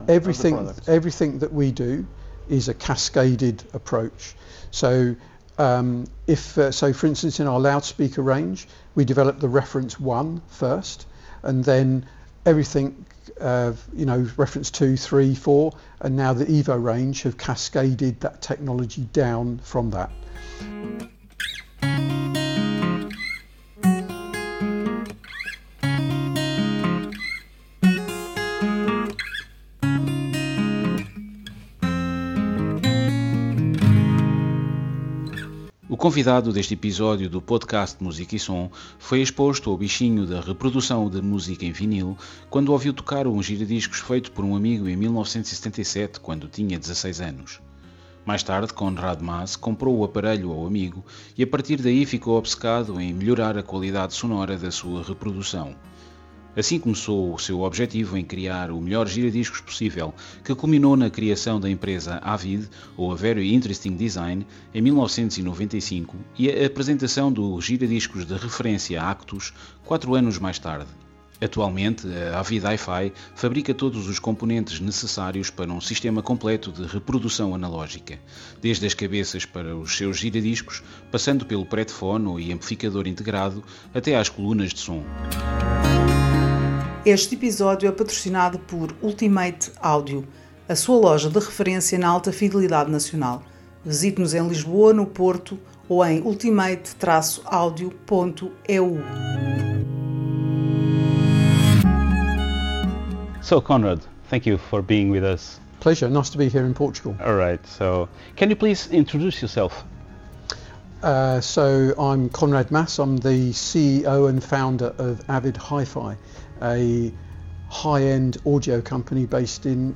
Esta é uma entrevista em língua inglesa.